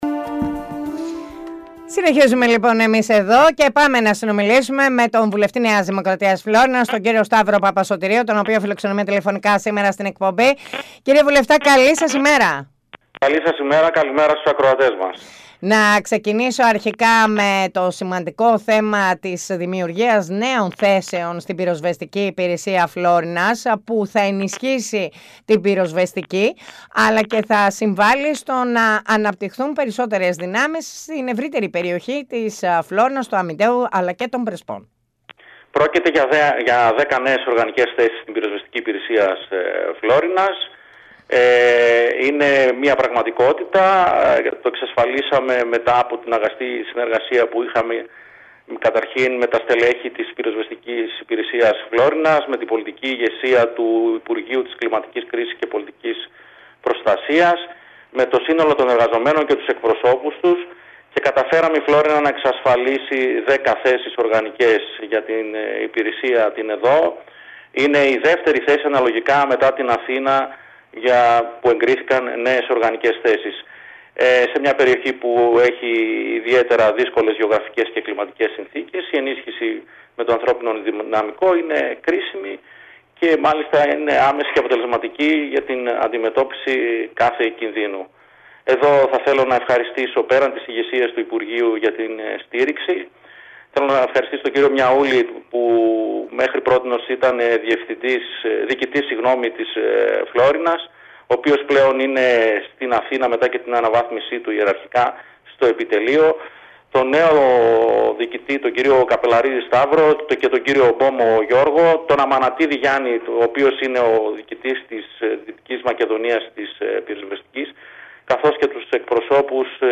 Στη σημερινή εκπομπή καλεσμένος είναι ο βουλευτής Ν.Δ. Φλώρινας Σταύρος Παπασωτηρίου ο οποίος μίλησε για την ενίσχυση των σωμάτων ασφαλείας Φλώρινας και των ενόπλων δυνάμεων με προσωπικό, για έργα που βρίσκονται υπό εξέλιξη και έργα που πρόκειται να γίνουν στην Περιφερειακή Ενότητα Φλώρινας.
ΣΥΝΕΝΤΕΥΞΗ